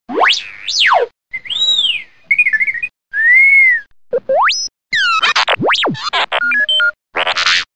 Звуки дроида R2D2 из звёздных войн в mp3 формате